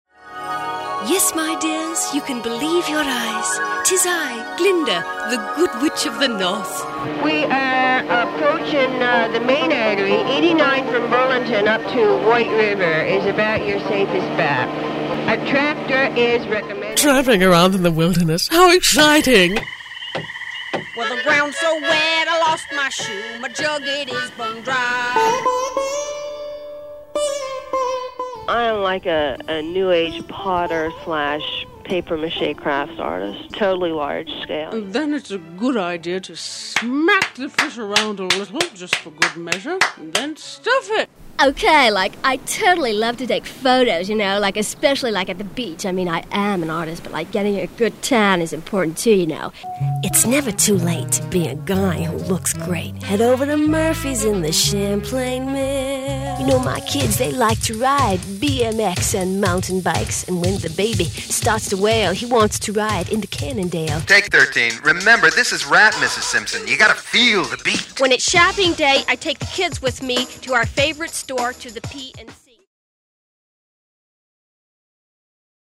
Cliff House Audio Voices